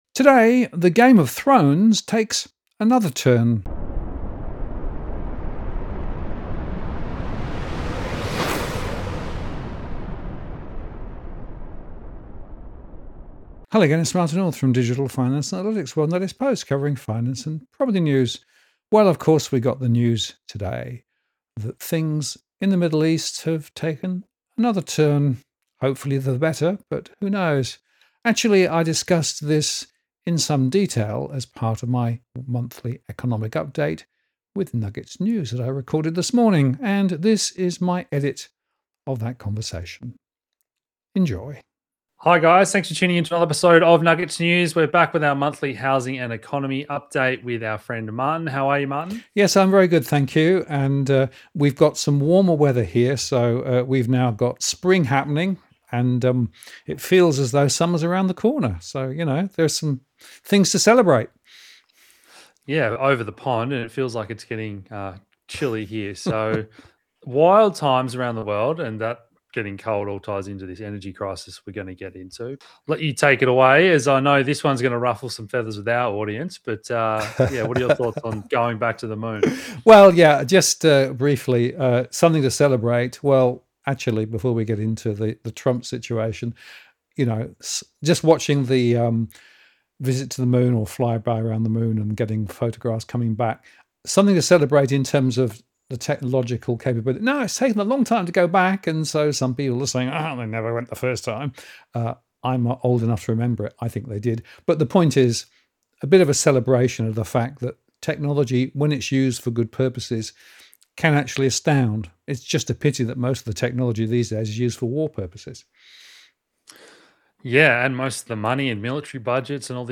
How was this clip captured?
We look at the impact of the temporary ceasefire in the Gulf War as part of our monthly economic update, recorded with Nuggets News. This is my edit of the discussion, with added slides. Who will be the winners? http